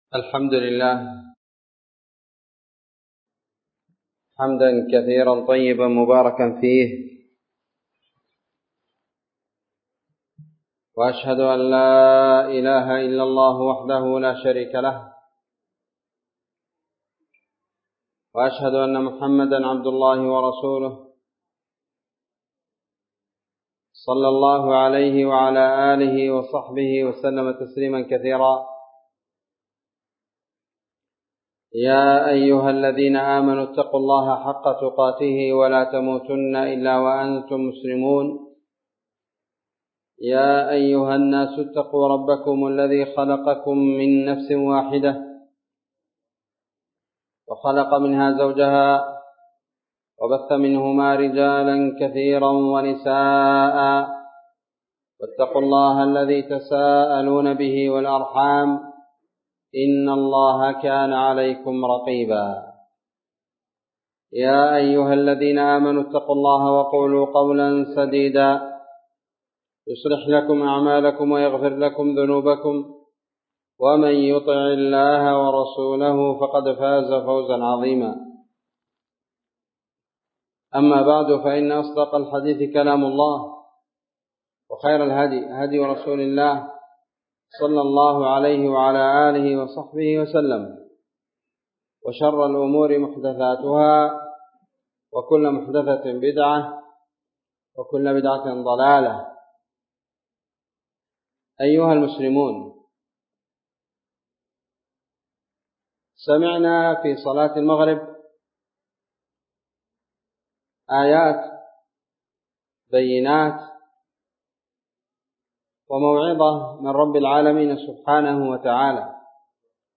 موعظة في تفسير آيات من سورة فاطر من قوله (ثم أورثنا الكتاب الذين اصطفينا من عبادنا ) الآية ٣٢ إلى الآية رقم ٣٨